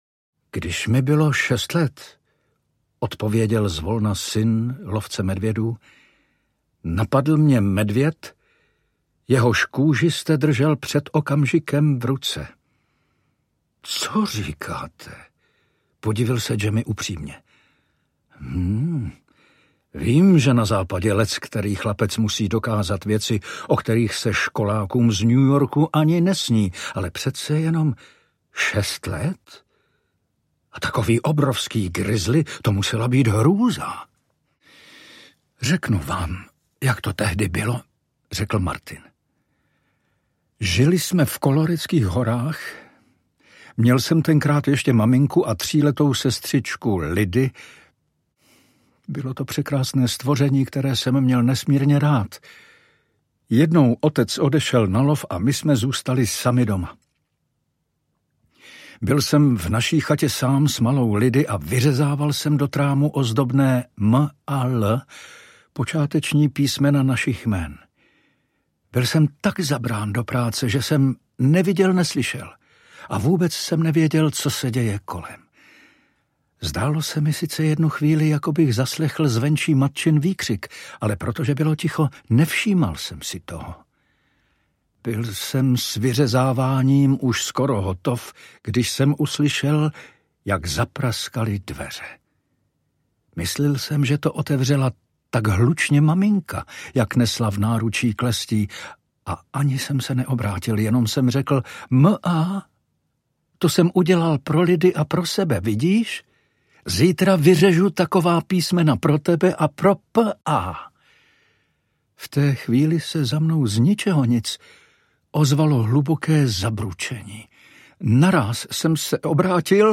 Syn lovce medvědů audiokniha
Ukázka z knihy
Vyrobilo studio Soundguru.